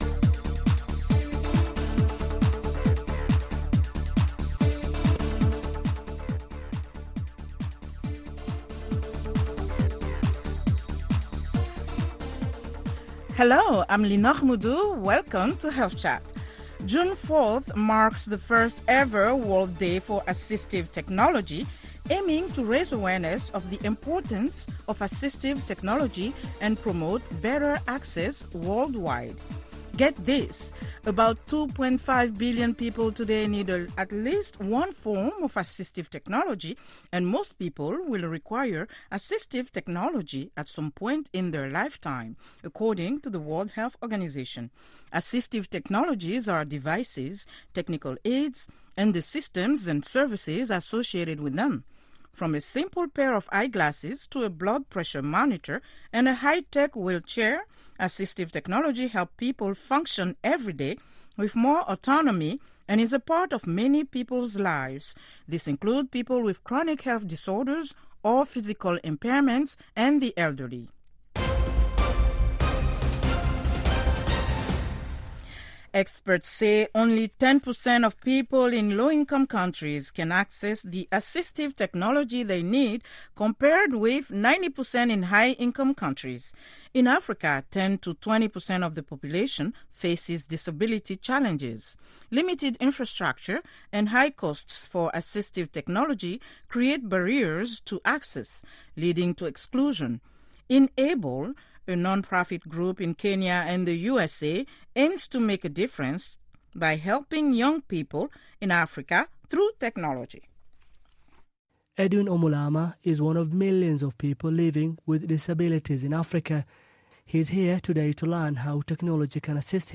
Health Chat is a live call-in program that addresses health issues of interest to Africa. The show puts listeners directly in touch with guest medical professionals. It includes a weekly feature spot, news and comments from listeners.